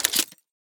select-shotgun-3.ogg